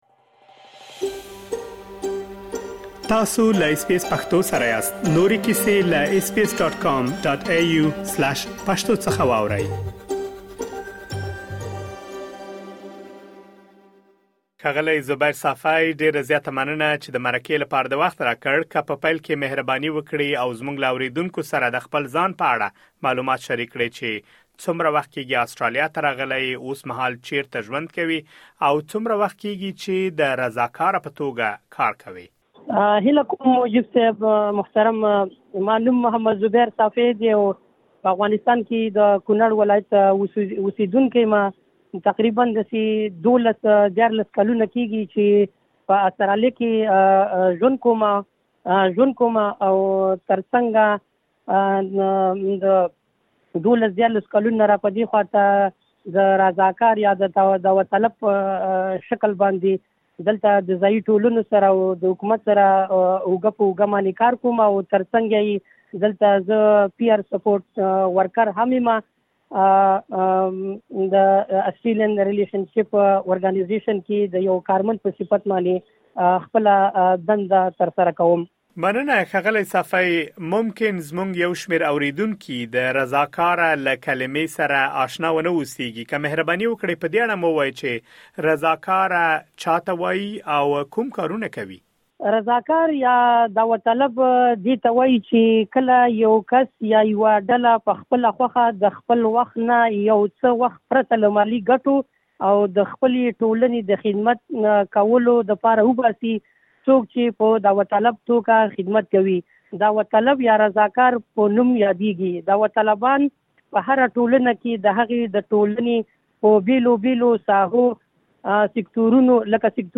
ایا پوهیږئ چې رضاکارانه کار کومې ګټې لري؟ مهرباني وکړئ لا ډېر معلومات په ترسره شوې مرکې کې واورئ.